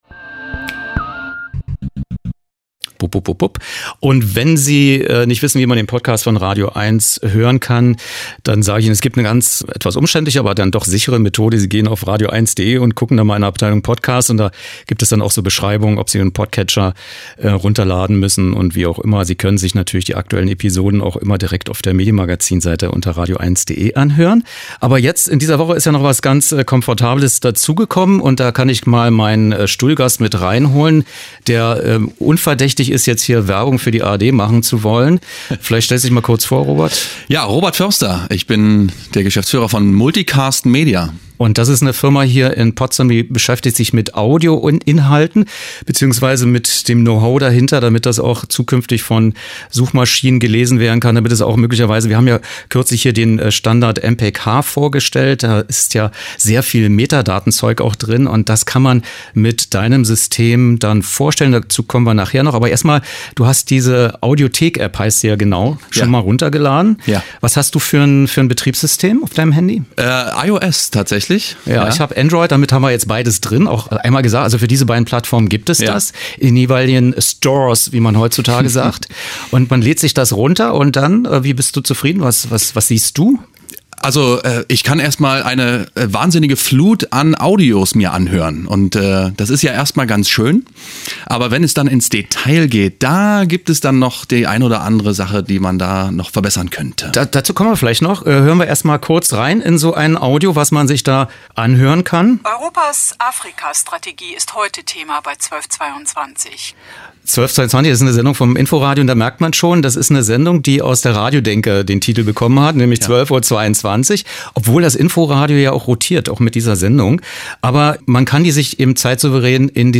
Gespräch über die wachsende Bedeutung von audio-on-demand-Angeboten am Beispiel der neuen ARD Audiothek App
Peter Lohmeyer, Schauspieler
Berlin, Hotel Westin Grand, Friedrichstraße 158-164